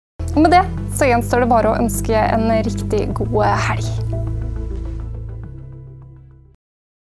Bloggeren husker fremdeles da det en gang ble satt over til Spjælkavik – uttalt med samme -æl- som i klippet nedenfor.
Klikk for å høre riktig NRK-uttale.